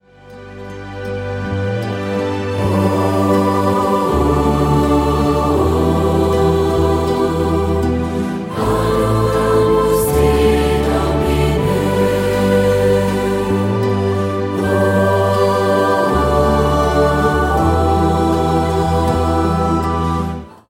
Keyboard
Piano
Gitarren
Bass
Ukulele
Querflöte
Sopran-, Tenor-, Altflöte
Djembe
Percussion
Regenrohr